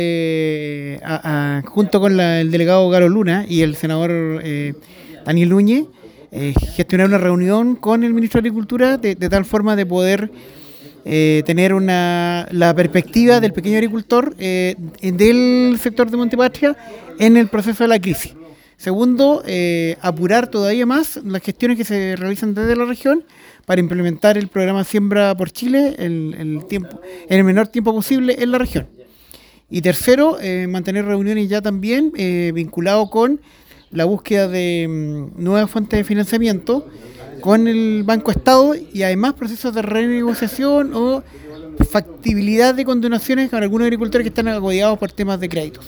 Ante esta situación de emergencia, diferentes autoridades llegaron hasta la localidad de El Palqui, con el fin de dialogar con los afectados y buscar soluciones concretas.
El seremi de Agricultura, en tanto, en el mismo sentido agrega que
SEREMI-AGRICULTURA.mp3